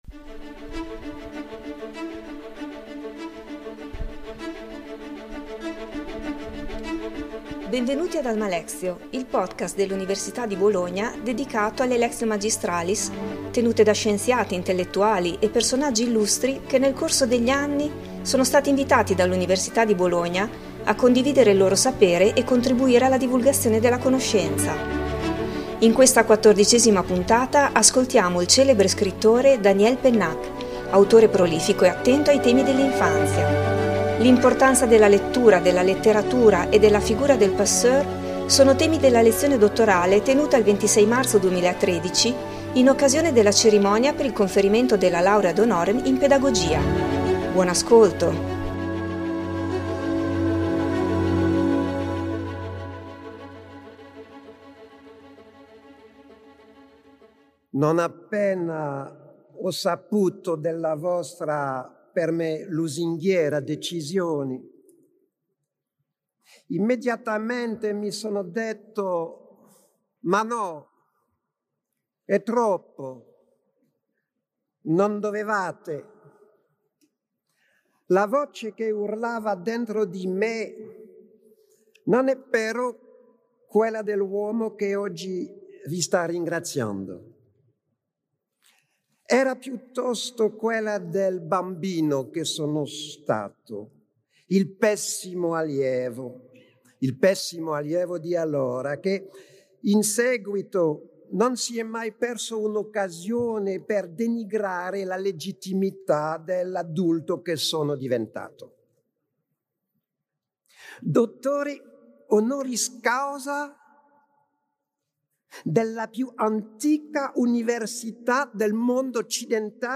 Il 26 marzo 2013 il celebre scrittore Daniel Pennac ha ricevuto la Laurea ad honorem in Pedagogia.“Une leçon d’ignorance” è il titolo della lezione dottorale, in cui affronta i temi dell'infanzia e dell’importanza della lettura, della letteratura e, soprattutto, della figura del “passeur”.